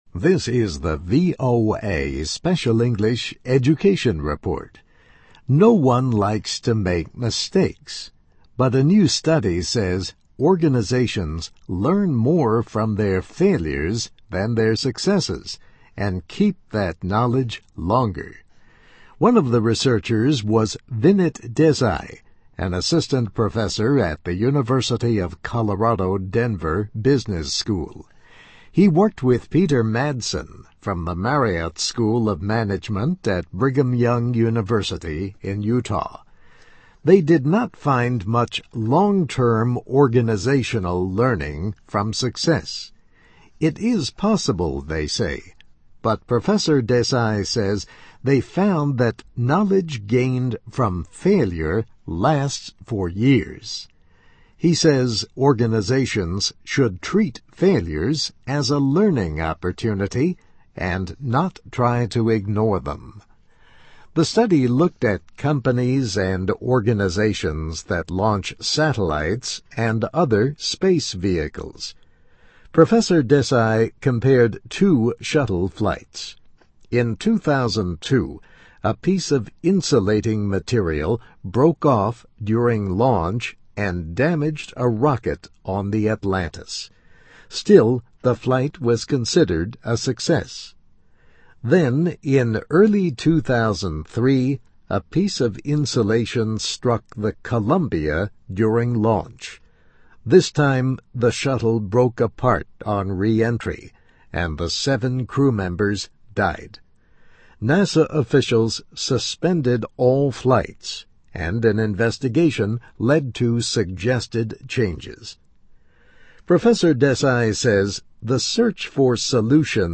Education Report